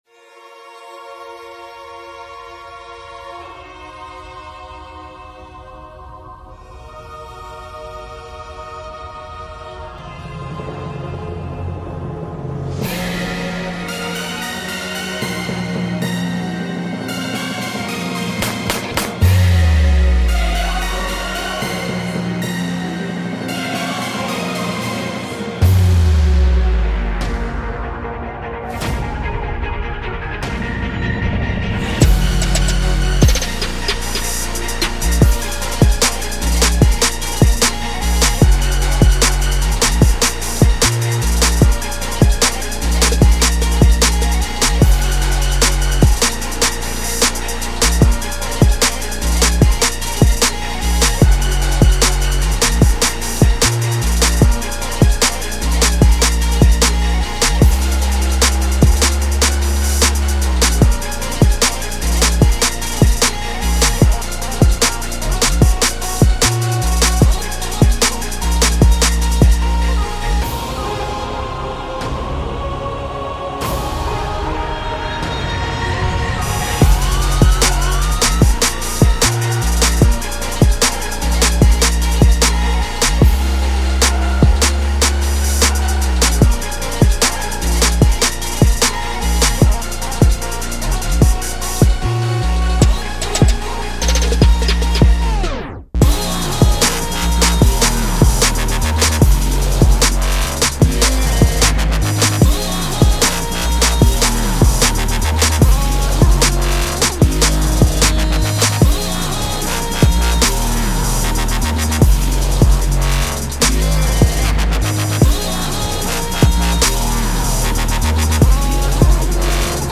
Schöner Beat, aber das ist keine Runde...